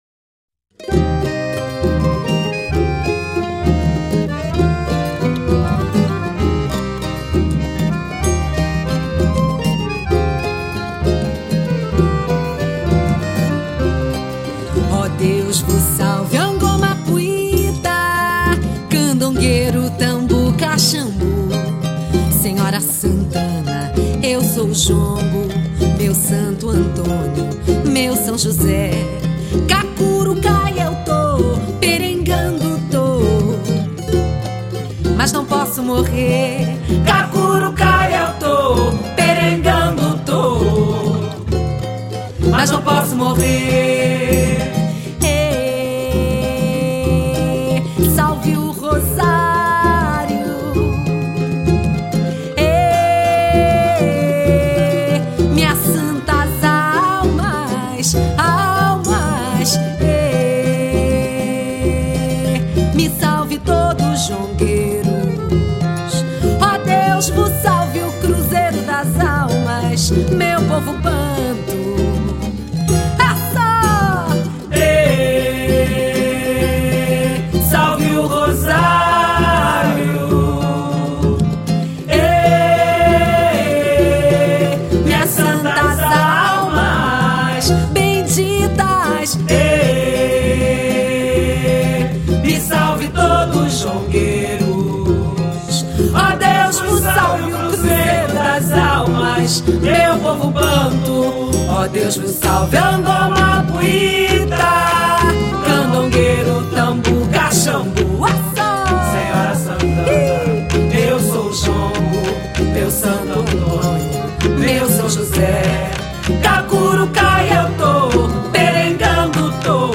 jongo